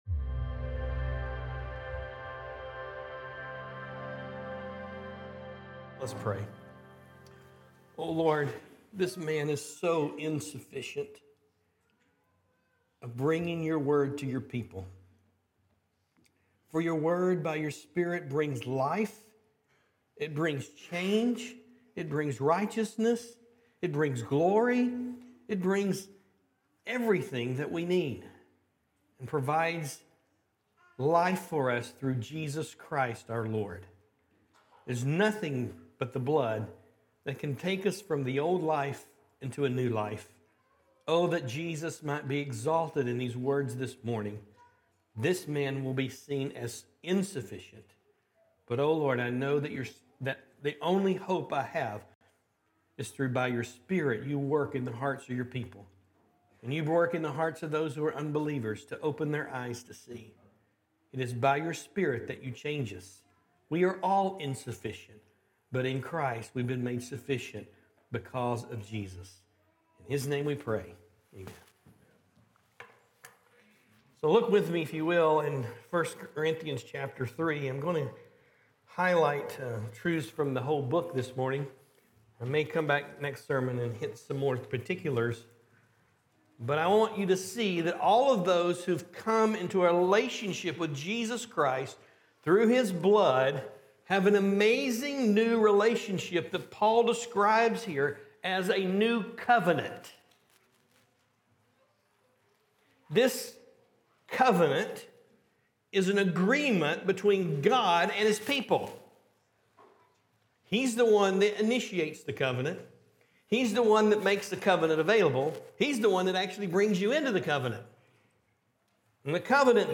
Sermon Series - Corydon Baptist Church - A Christian Family of Disciple Making Disciples